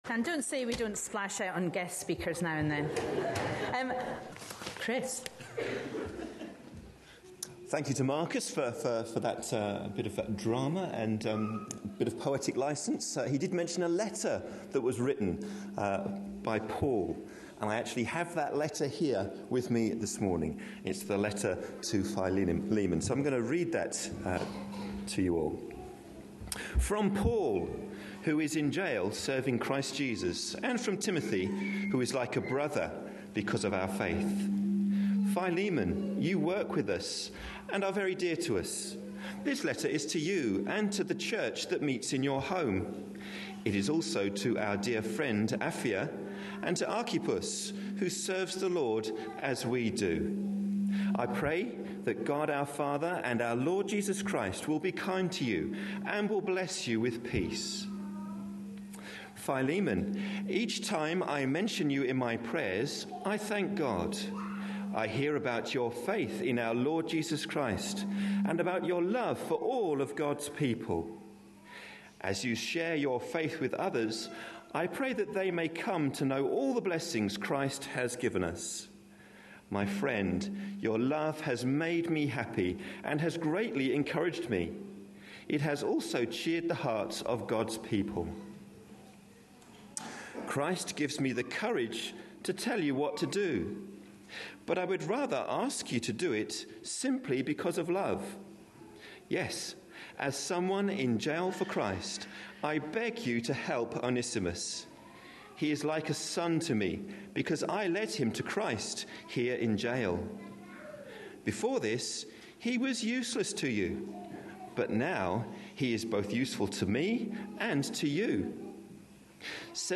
A sermon preached on 20th January, 2013.